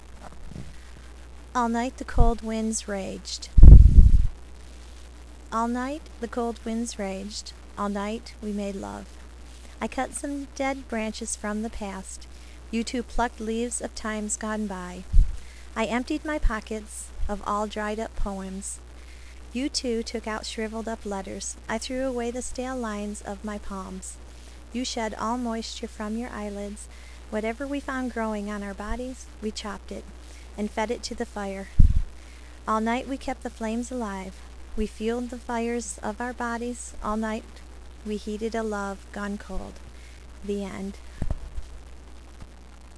A Voice Reading of A friends Poem